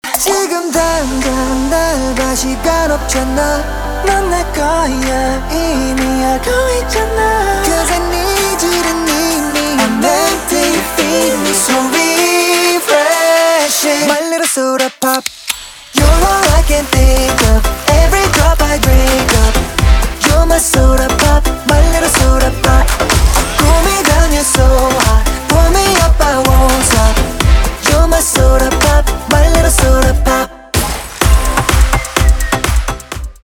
k-pop
свист
битовые